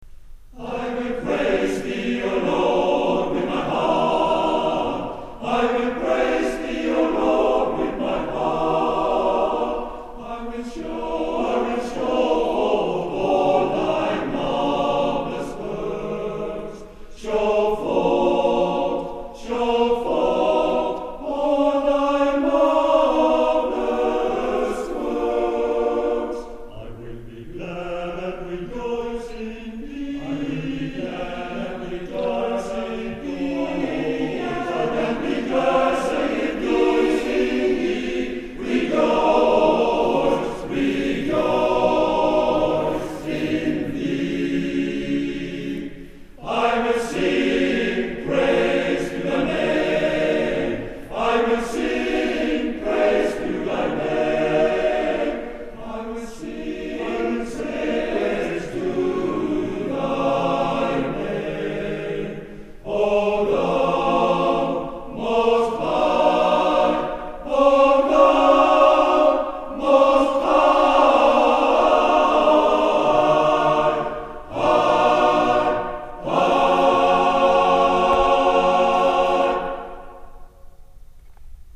Konsertopptak
GrieghallenIwillpraise.mp3